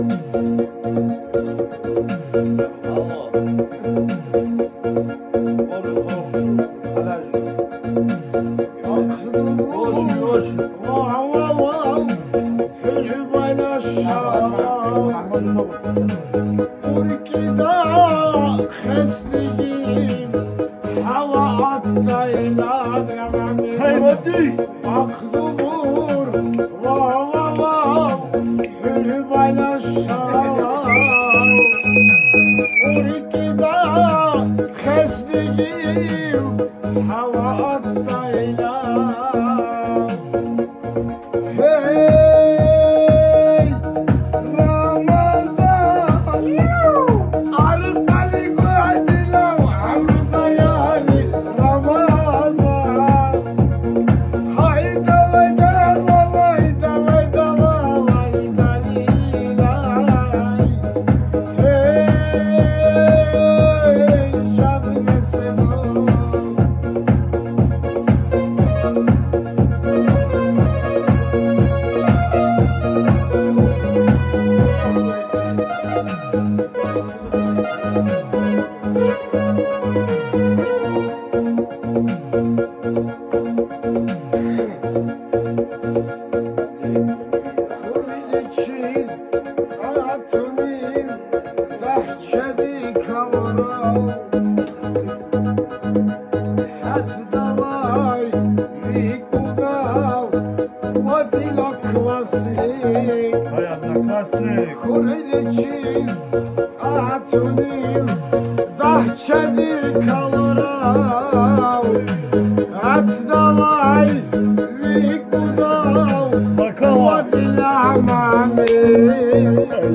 Дагестанская песня